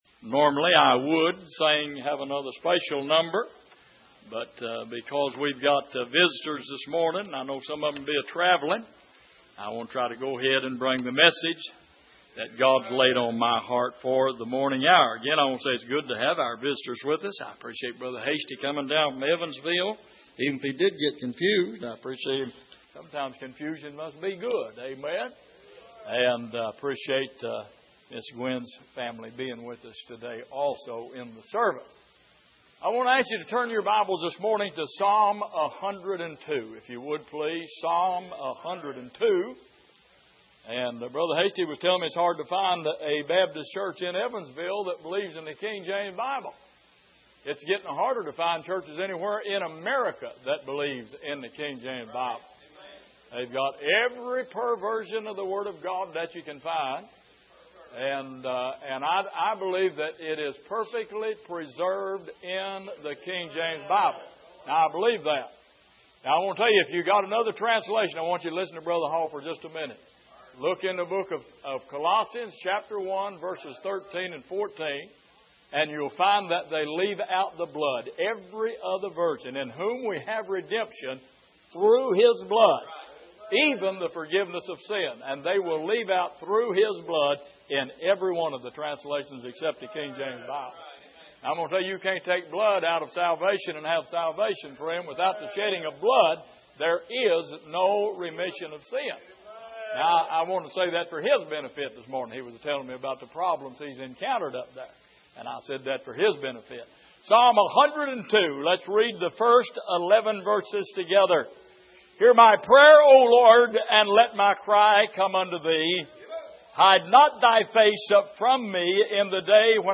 Passage: Psalm 102:1-11 Service: Sunday Morning